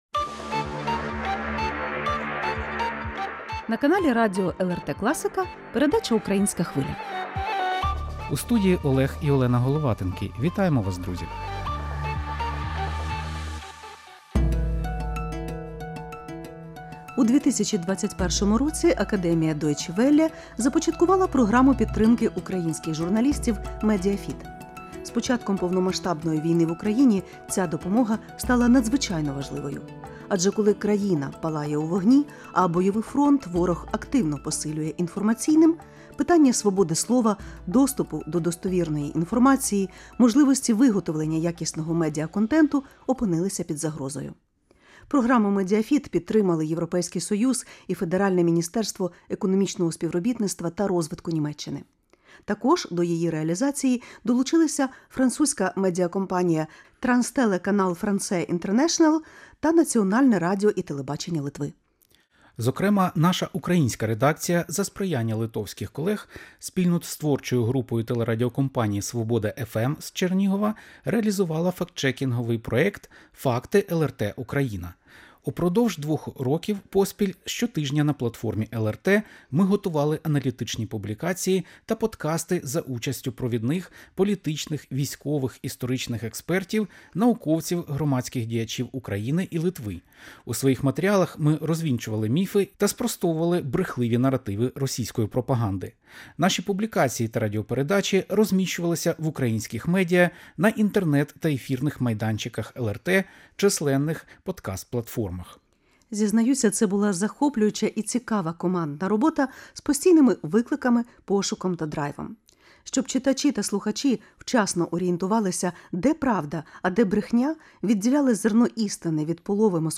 Як MediaFit посприяла боротьбі з дезінформацією, захисту свободи слова та розвитку творчих проєктів? Про все говоримо з координаторами програми та українськими журналістами.